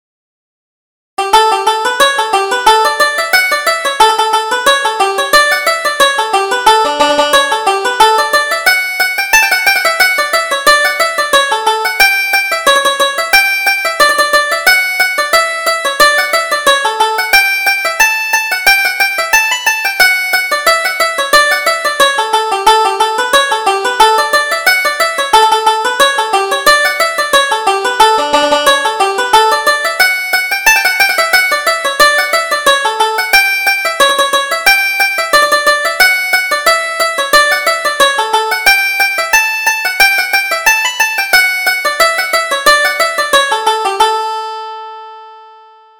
Reel: Captain Rock